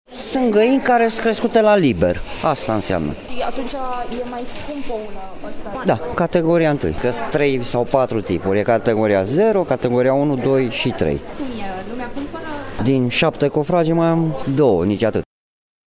Dacă în urmă cu două-trei săptămâni, un ou costa maxim 50 de bani, acum, într-o piață din Brașov, prețul mediu este de 70 de bani.
vanzator-oua.mp3